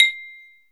BELL BALA.wav